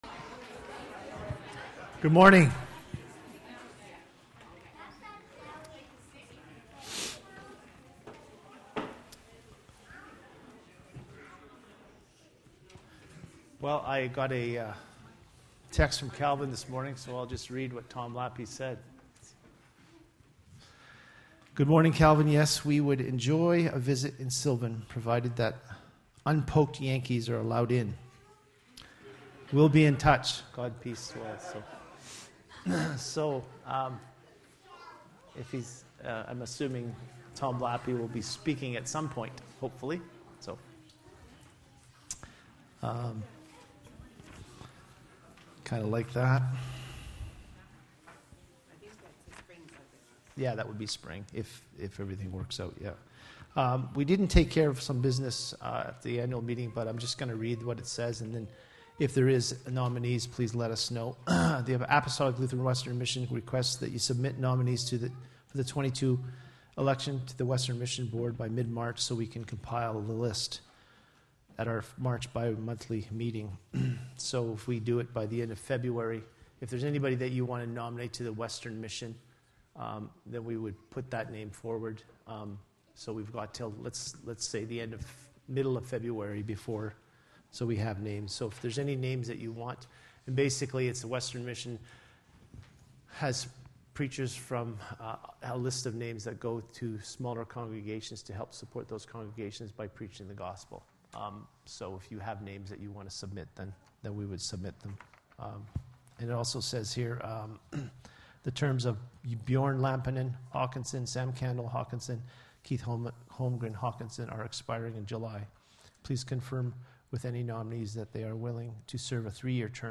Passage: Luke 2: 41-52 Service Type: Sunday Service